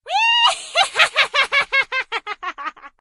witch.ogg